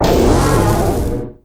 Cri de Salarsen dans sa forme Grave dans Pokémon Épée et Bouclier.
Cri_0849_Grave_EB.ogg